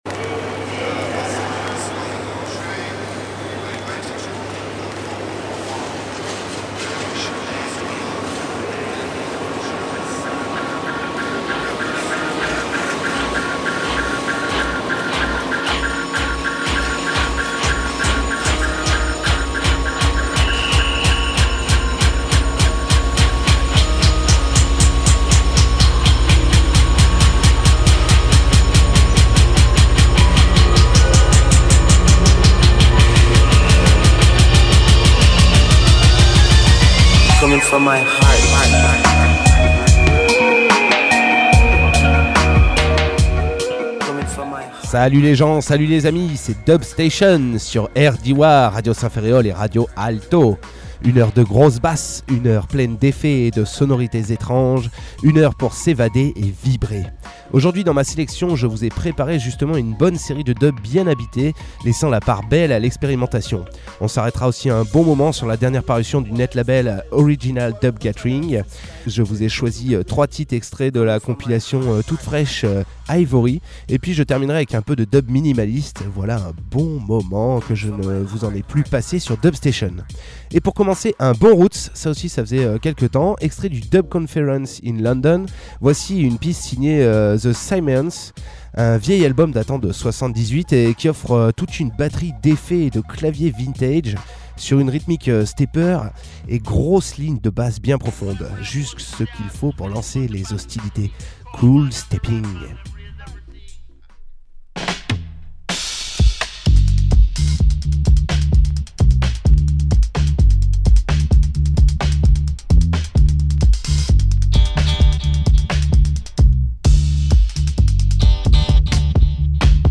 bass music
musique electronique , reggae